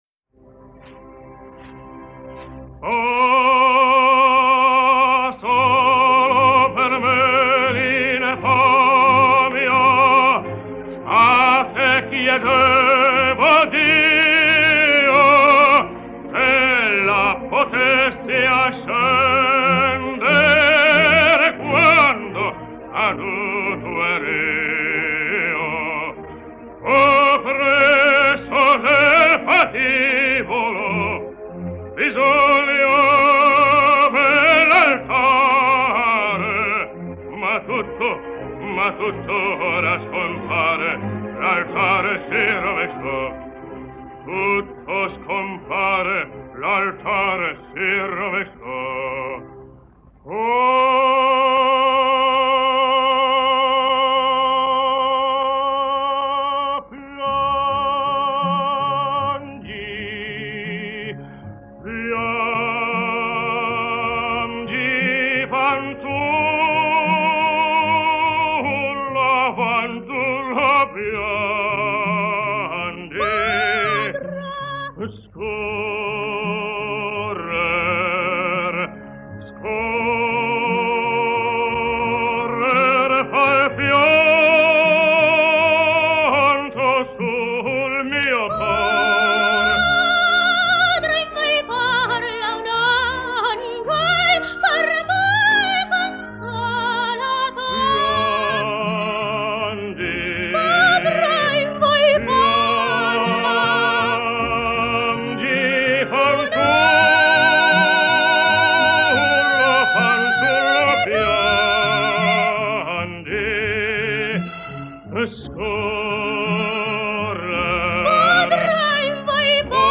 98 лет со дня рождения итальянского певца (баритон) Джино Беки (Gino Bechi)